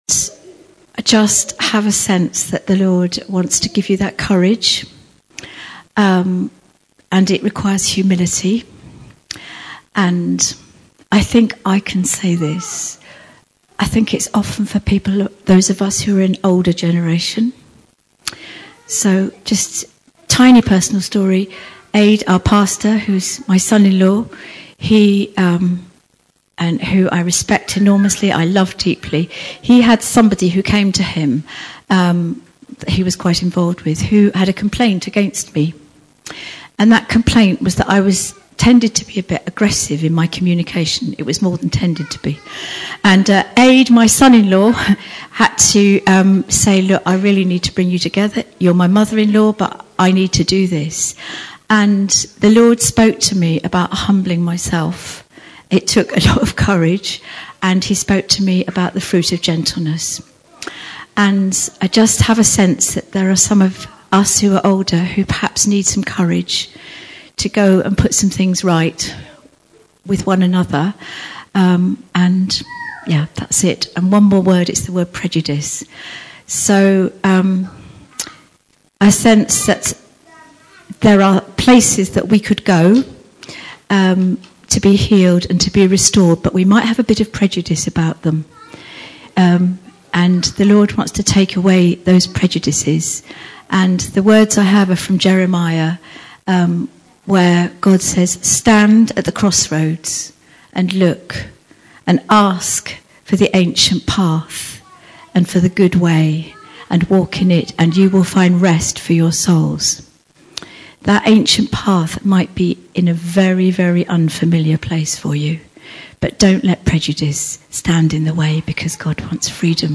Church Weekend at Brunel Manor | City Life Church
Here is your chance to listen again to the teaching sessions.